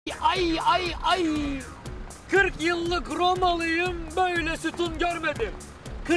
Ayayayayaya Sound Buttons